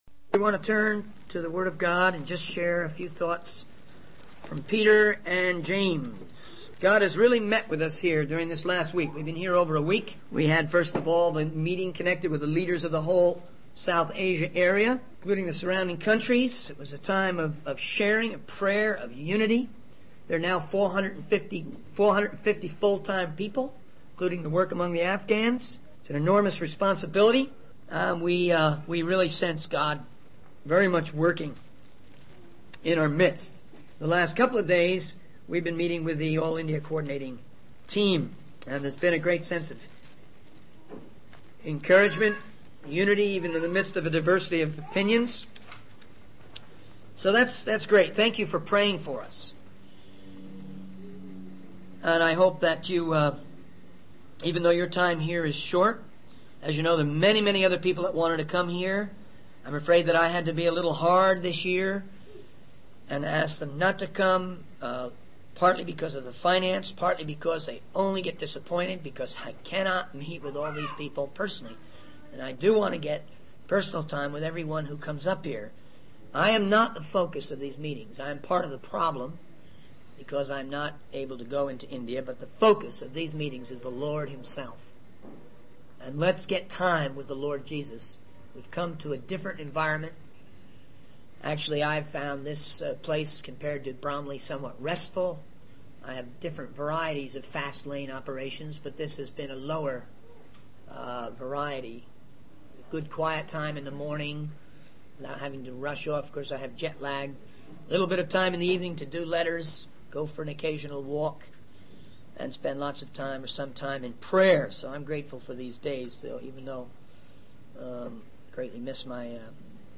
In this sermon, the speaker shares about their recent meetings and experiences in South Asia.